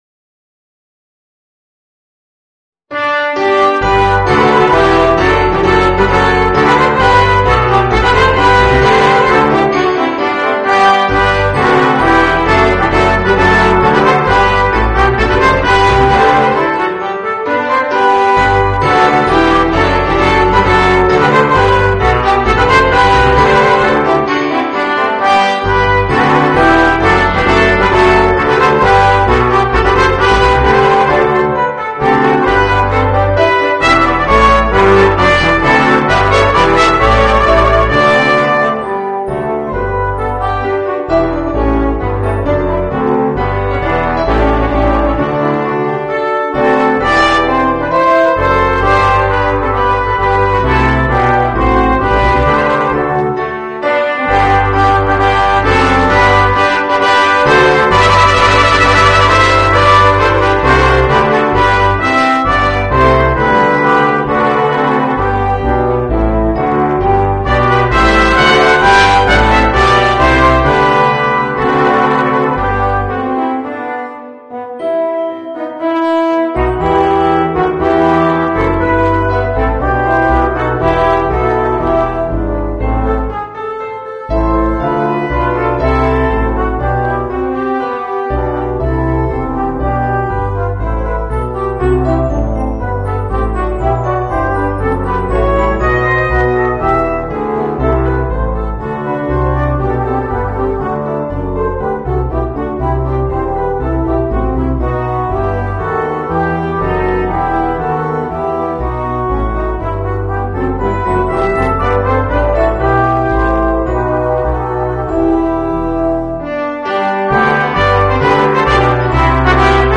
Voicing: 4 - Part Ensemble and Piano